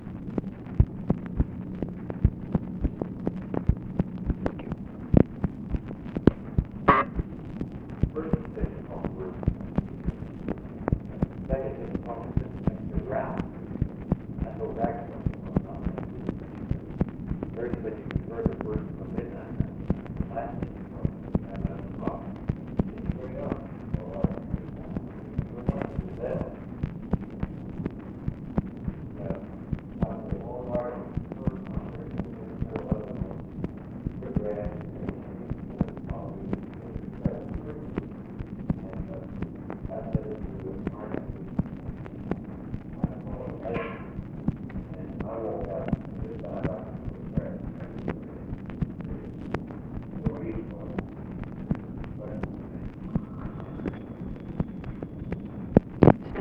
OFFICE CONVERSATION, February 25, 1964
Secret White House Tapes